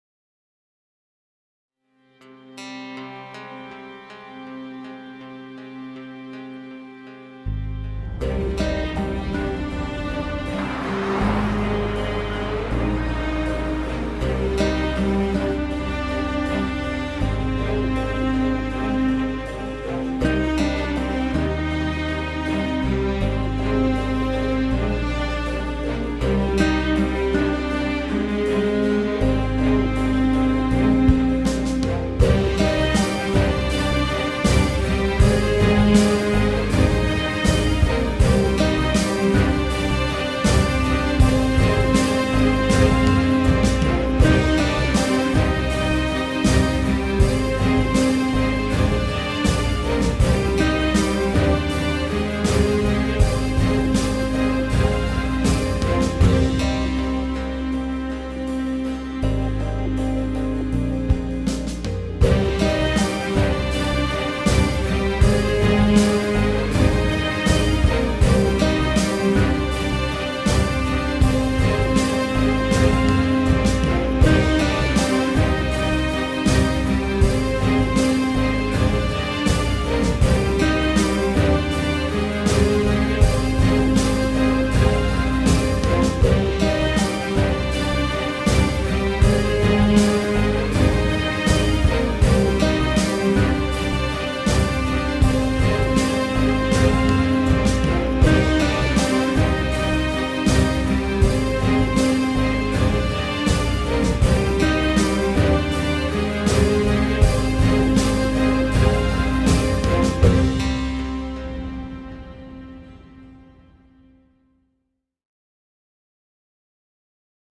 duygusal hüzünlü gerilim fon müziği.